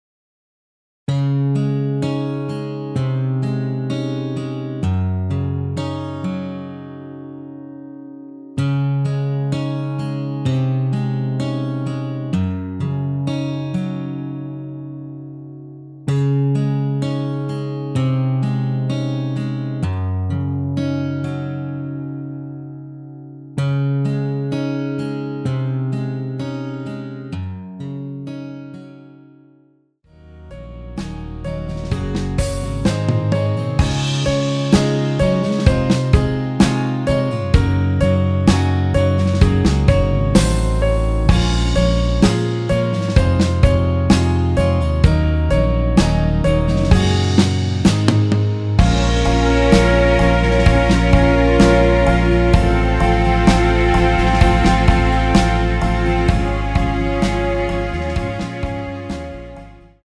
원키에서(-1)내린 MR입니다.
Db
앞부분30초, 뒷부분30초씩 편집해서 올려 드리고 있습니다.
중간에 음이 끈어지고 다시 나오는 이유는